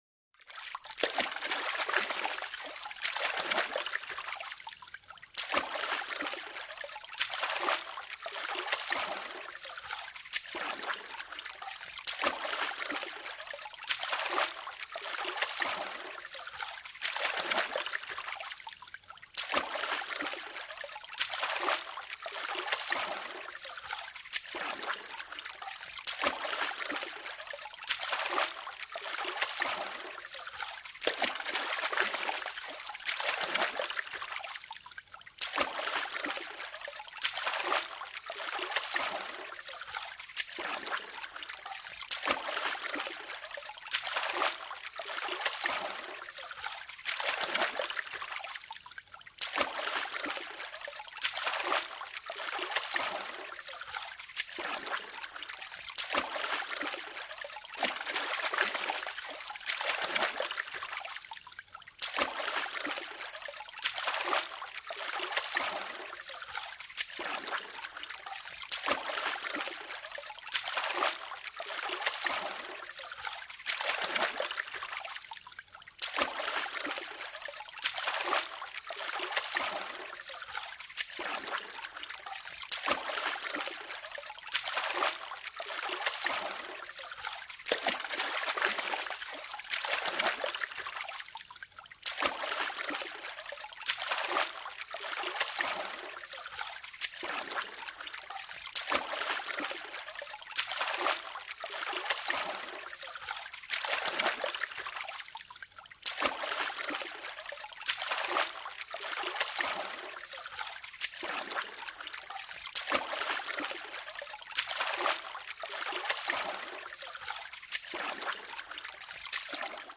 Paddle Boat - Slow